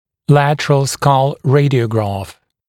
[‘lætərəl skʌl ‘reɪdɪəugrɑːf][‘лэтэрэл скал ‘рэйдиоугра:ф]боковая рентгенограмма черепа